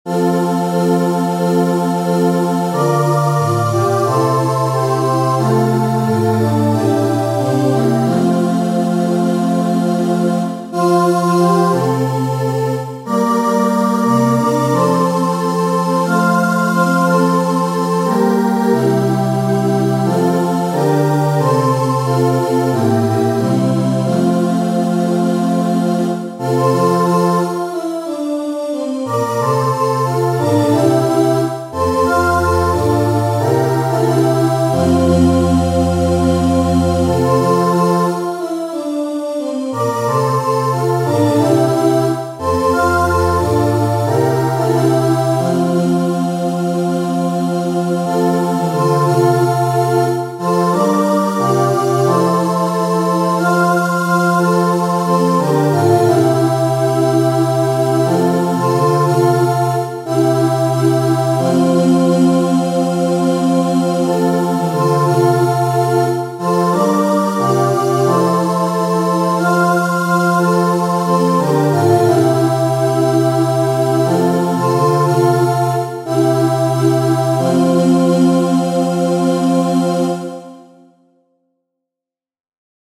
• Catégorie : Chants de Sanctus